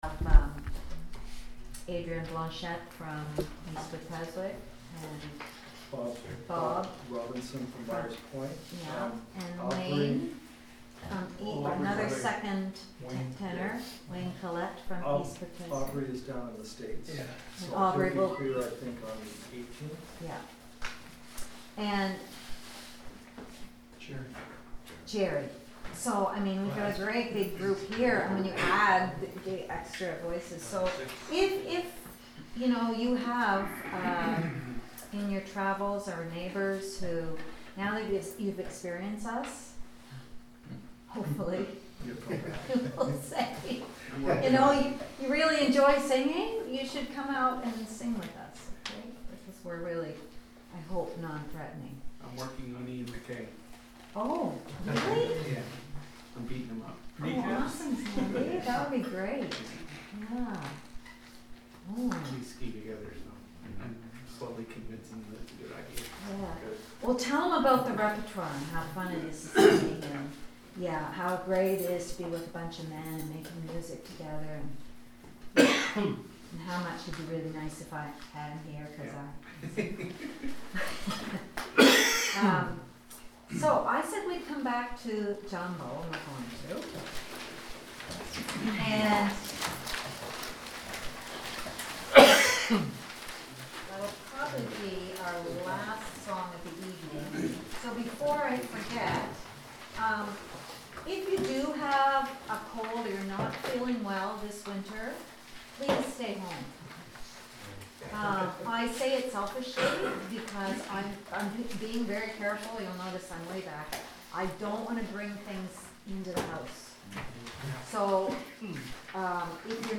Rehearsal Files